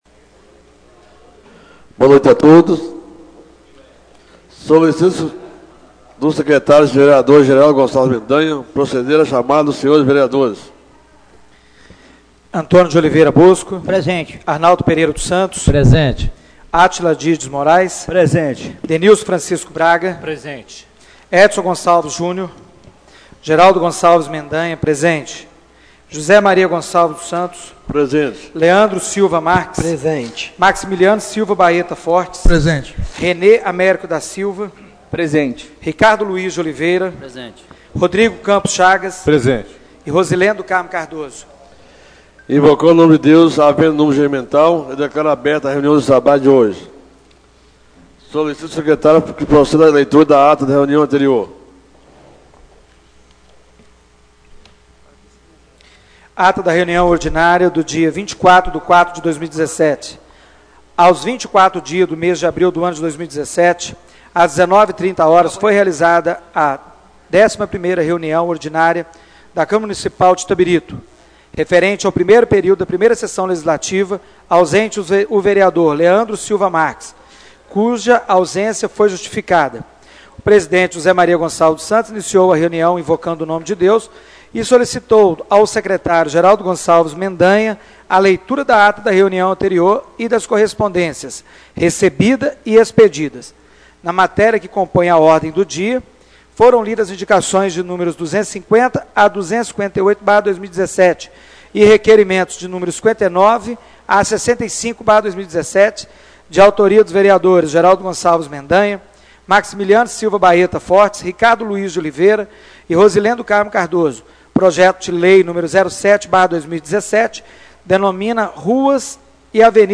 Reunião Ordinária do dia 08/05/2017